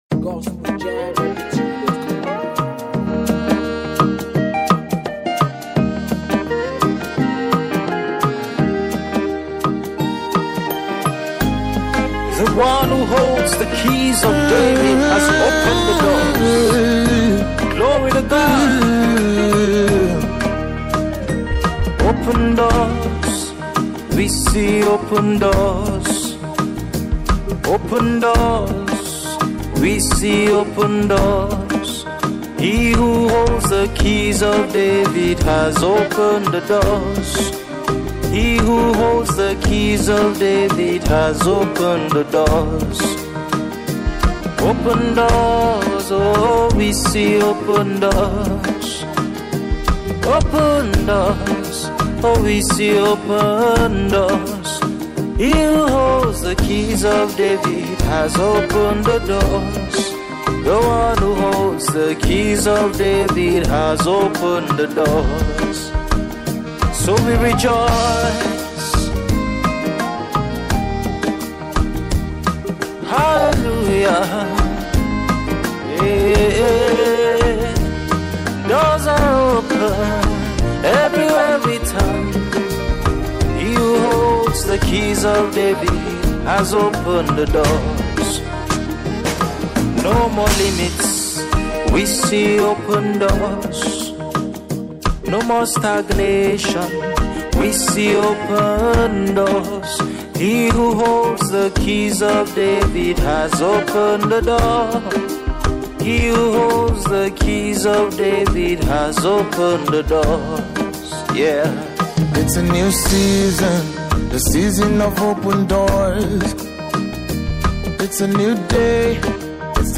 prophetic song